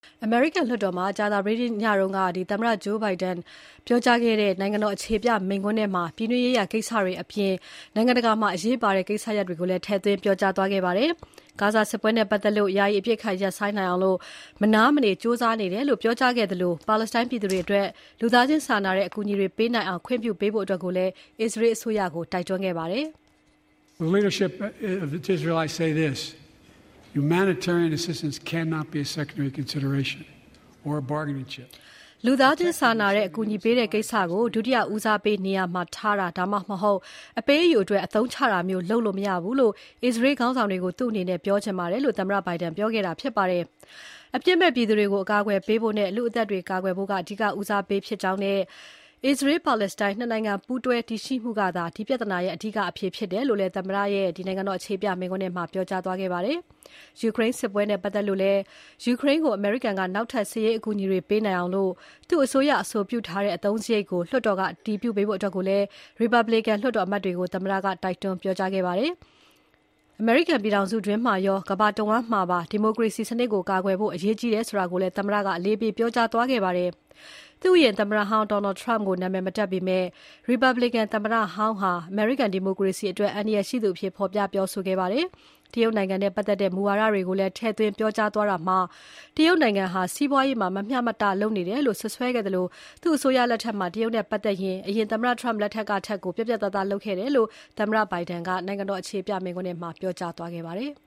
သမ္မတ Biden ရဲ့ နိုင်ငံတော်အခြေပြမိန့်ခွန်း
အမေရိကန်လွှတ်တော်မှာ သမ္မတ Joe Biden ကြာသပတေးညက ပြောကြားခဲ့တဲ့ နိုင်ငံတော်အခြေပြ မိန့်ခွန်းထဲမှာ ပြည်တွင်းရေးရာကိစ္စတွေကို အသားပေးပြောခဲ့သလို အရေးပါတဲ့ နိုင်ငံတကာကိစ္စတချို့ကိုလည်း ထည့်သွင်းပြောသွားခဲ့ပါတယ်။